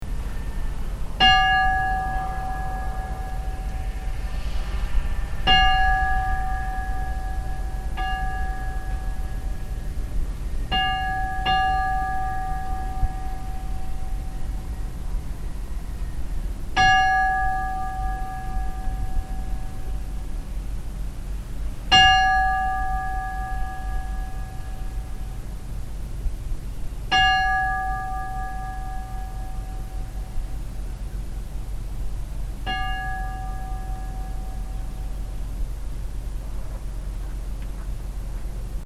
Klukkurnar eru tvær: Önnur kirkjuklukka með talsverðum áletrunum en hin skipsklukka og nokkru minni.
Að jafnaði er aðeins kirkjuklukkan notuð.
hellnakirkja_likhringing.mp3